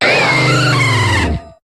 Cri de Dialga dans Pokémon HOME.